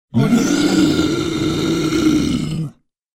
دانلود افکت صوتی بیست اتک غرغر 2
آهنگ صوتی رایگان Beast Attack Growl 2 یک گزینه عالی برای هر پروژه ای است که به صداهای بازی و جنبه های دیگر مانند sfx، هیولا و موجود نیاز دارد.